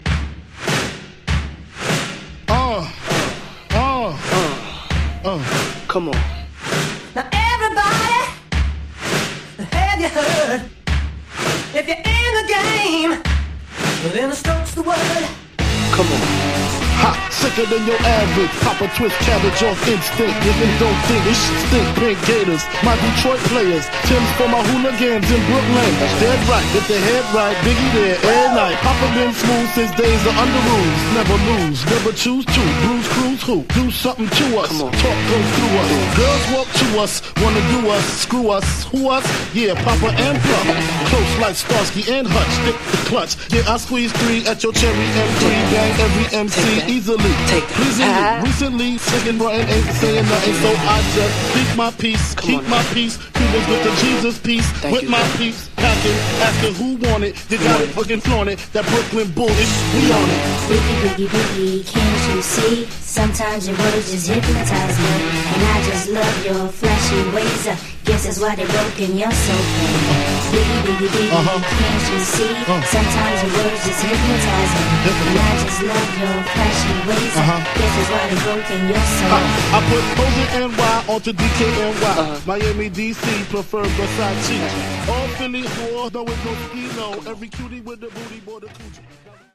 97 bpm
Clean Version